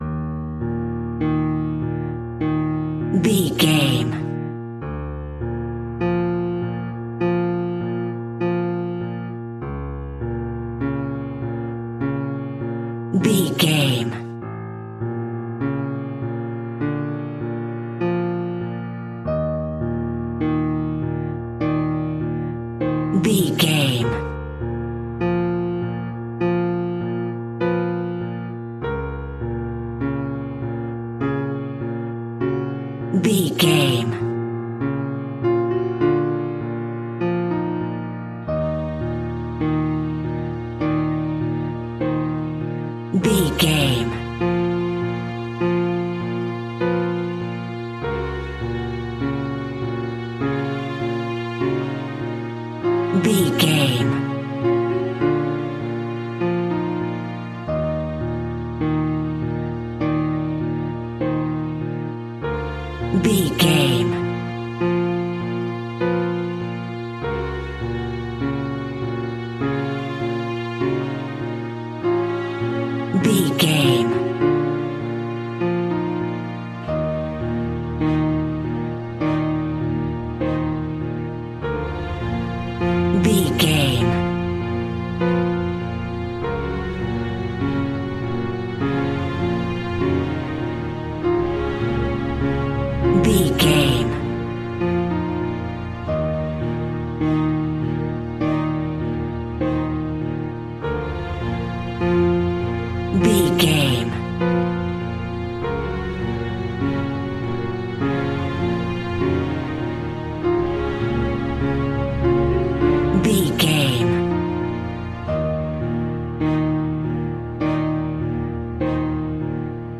In-crescendo
Thriller
Aeolian/Minor
scary
tension
ominous
dark
suspense
eerie
synths
Synth Pads
atmospheres